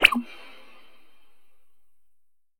【効果音】風が過ぎる「ヒューン」
風が通り過ぎるような音をシンセで再現した効果音素材です。寒いダジャレで風が過ぎるコミカルな使い方も。...